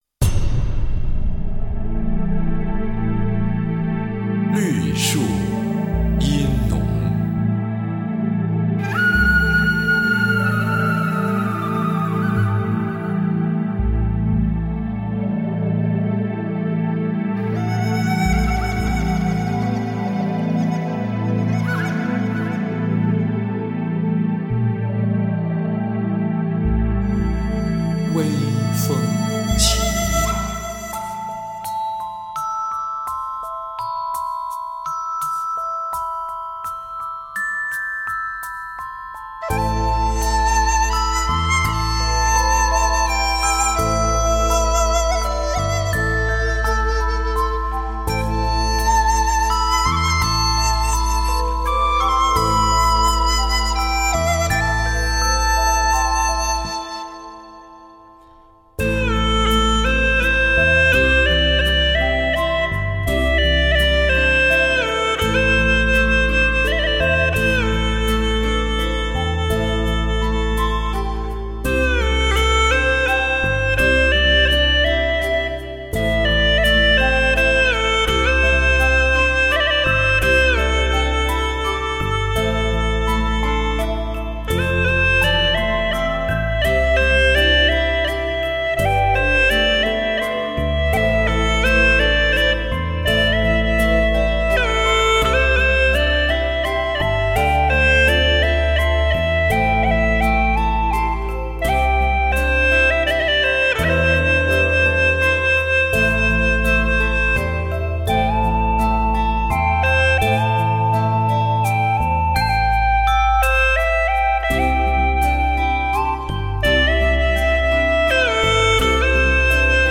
◀◀◀◀◀ 重金属的音质 娓娓动听的旋律 尽情感受发烧器乐 ▶▶▶▶▶